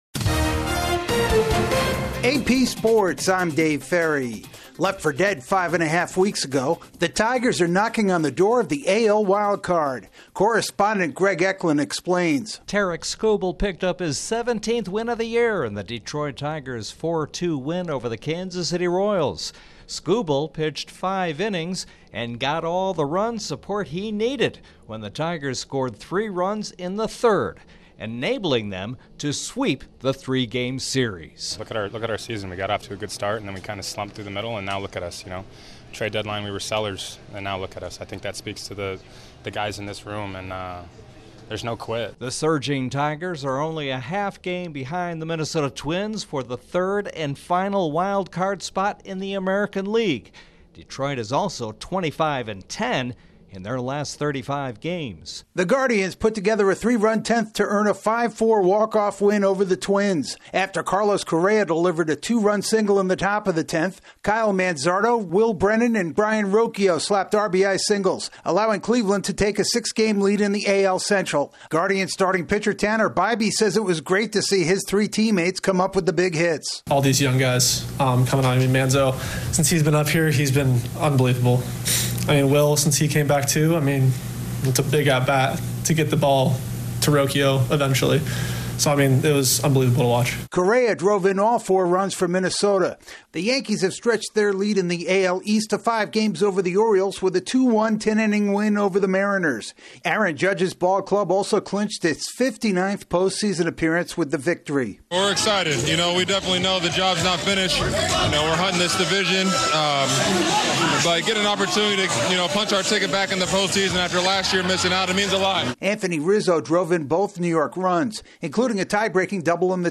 Headliner Embed Embed code See more options Share Facebook X Subscribe There's no taming the Tigers these days, the Guardians get a wild, walk-off win, the Brewers clinch before beating the Phillies, the four NL wild-card contenders all win, the Rams keep their top wideout off IR, the Packers practice with their top QB and the Bruins await their No. 1 netminder. AP correspondent